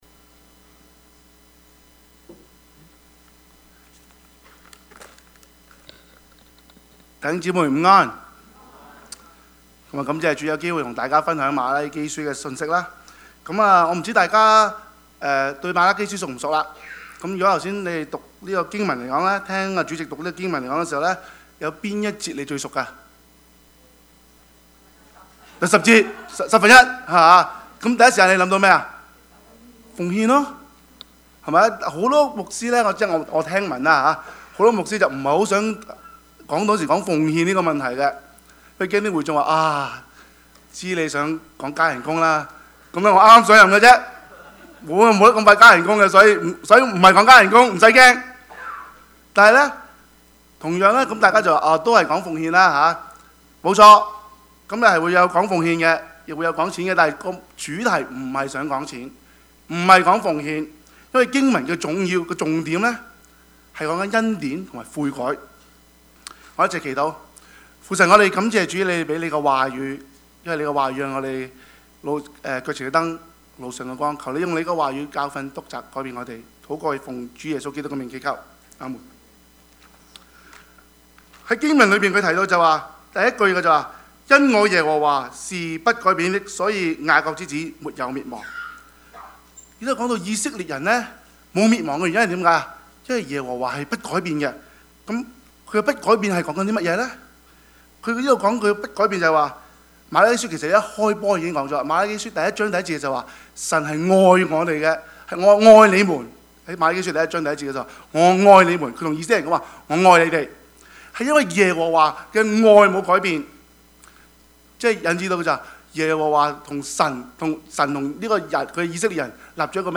Passage: 瑪拉基書 3:6-12 Service Type: 主日崇拜
Topics: 主日證道 « 神的國與主再來 一個母親的禱告 »